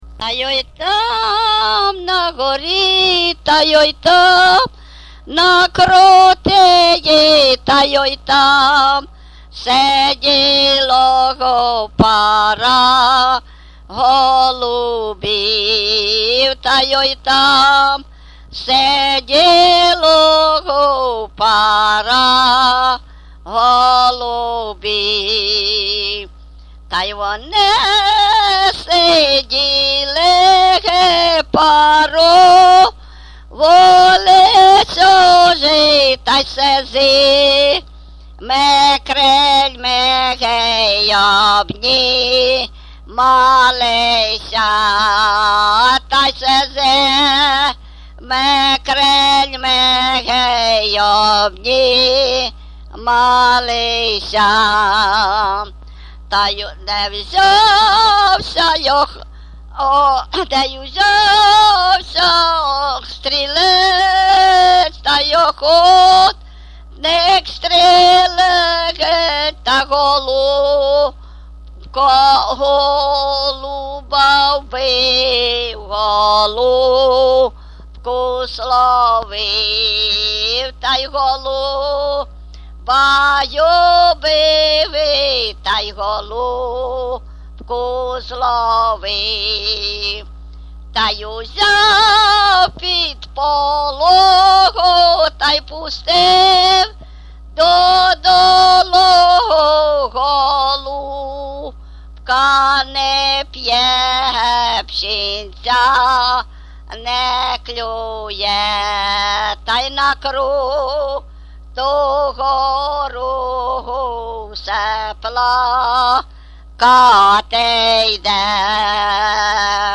ЖанрПісні з особистого та родинного життя, Балади
Місце записус. Нижні Рівні, Чутівський район, Полтавська обл., Україна, Слобожанщина